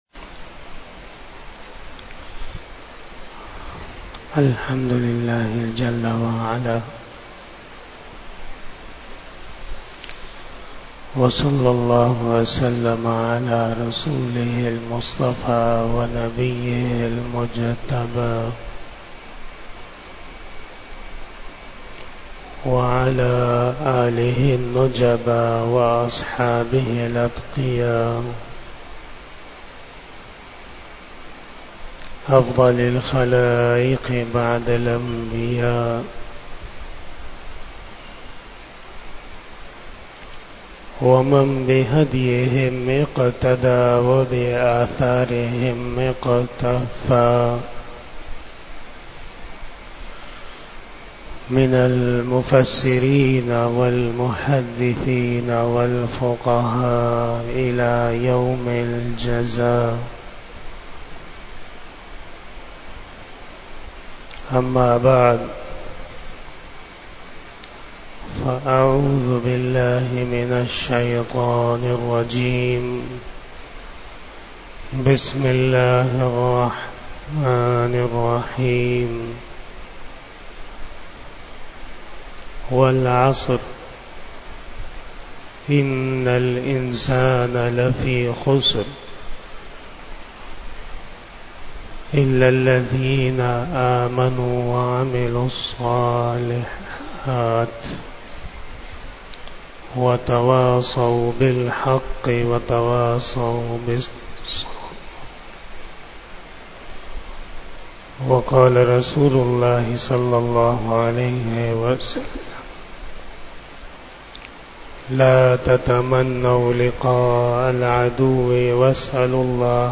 42 Bayan E Jummah 20 October 2023 (04 Rabi Us Sani 1445 HJ)